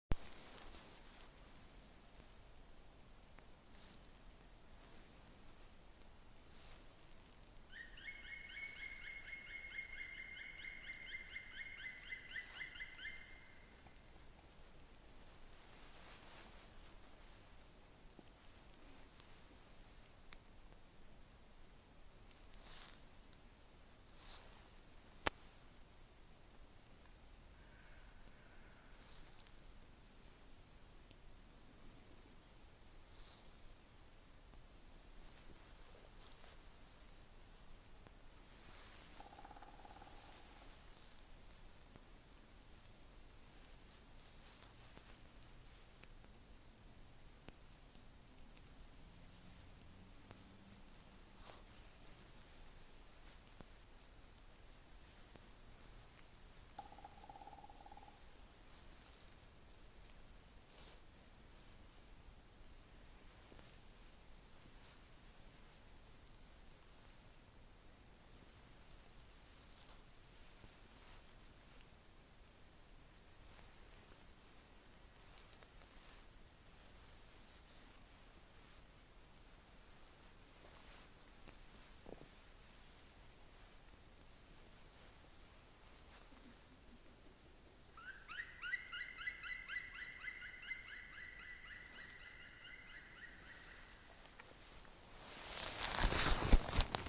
Birds -> Woodpeckers ->
Black Woodpecker, Dryocopus martius